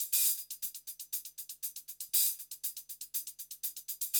HIHAT LO13.wav